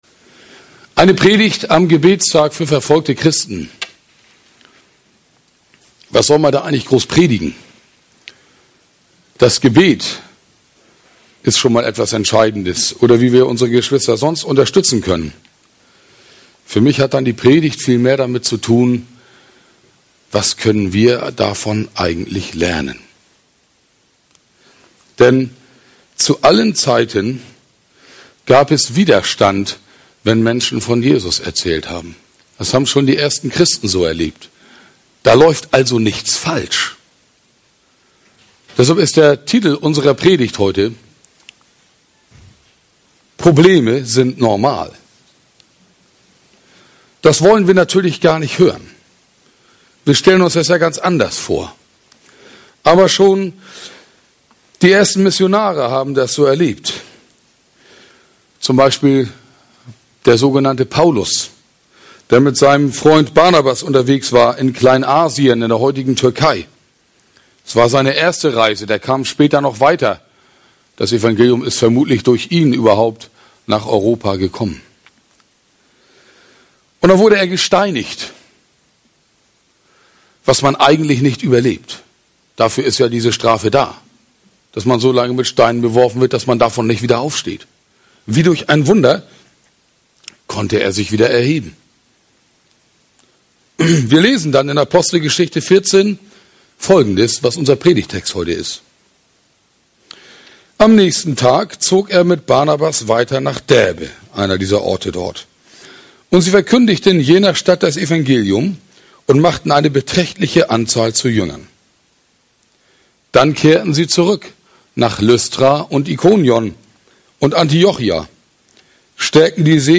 beim ProChrist Abschlussgottesdienst im Haus der FECG, Ratzeburg